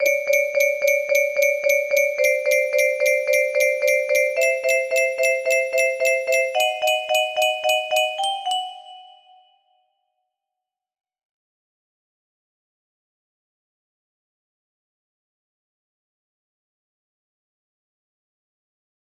Chopsticks music box melody
I tried making chopsticks on a music box, sorry if it’s out of tune